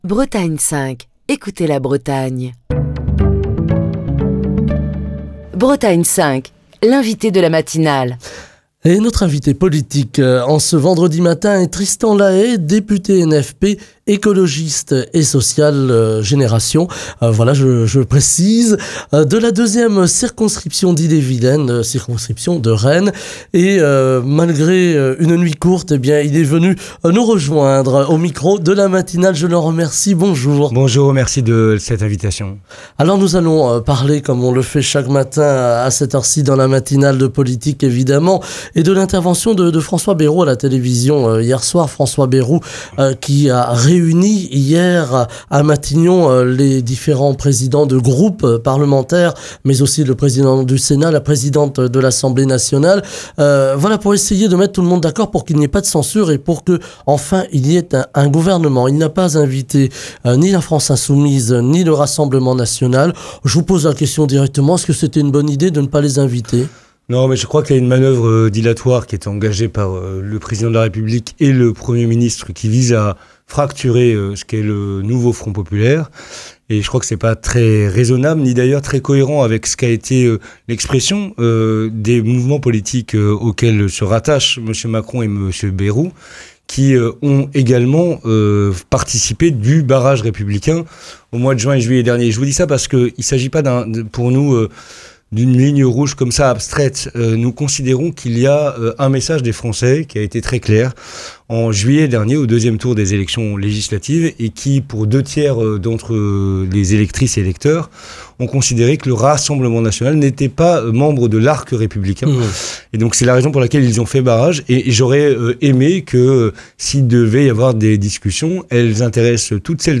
Ce matin, Tristan Lahais, député Génération.s du Nouveau Front Populaire, représentant du groupe Écologiste et Social pour la 2ᵉ circonscription d’Ille-et-Vilaine (Rennes), était l’invité de la matinale de Bretagne 5. Il s’est exprimé sur l’actualité politique, revenant notamment sur les déclarations faites par François Bayrou, la veille, lors de son passage sur France 2.